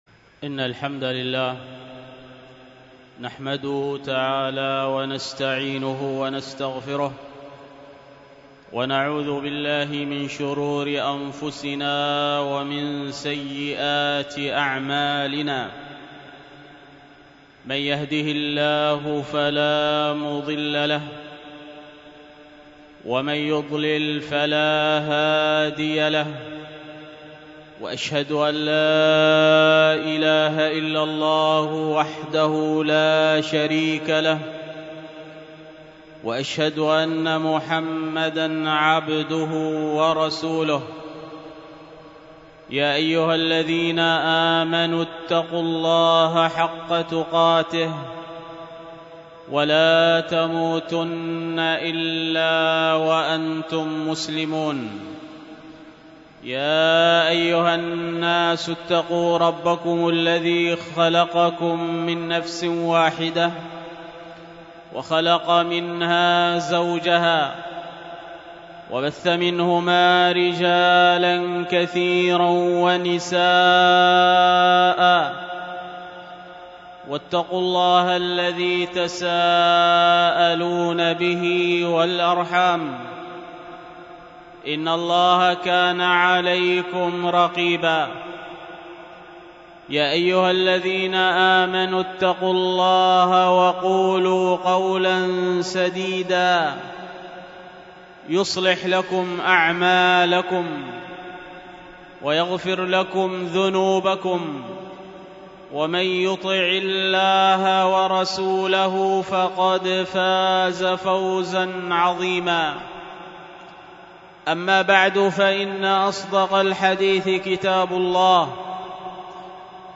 الخطبة بعنوان الوقت هو الحياة، والتي كانت بمسجد السنة بدار الحديث بطيبة